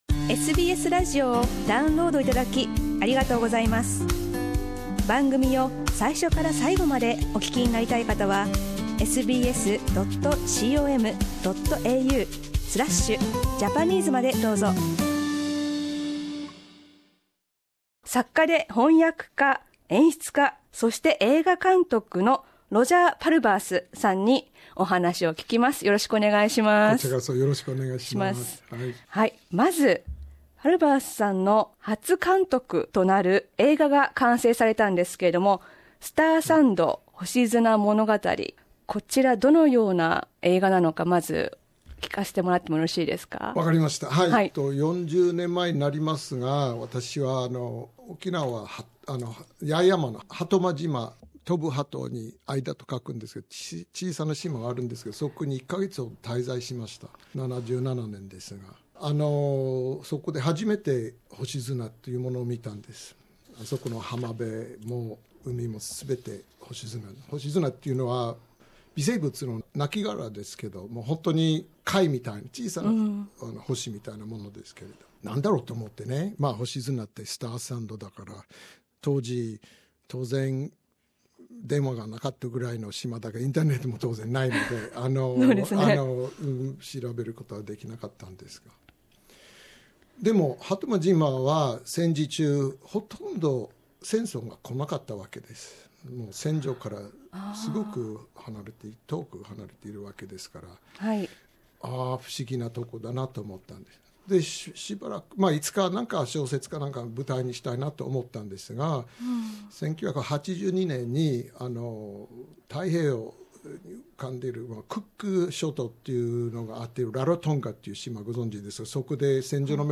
沖縄を舞台に、脱走兵に焦点を当てて、非暴力をテーマにした作品です。原作、脚本、監督を担当したパルバースさんに同作品についてお話を聞きました。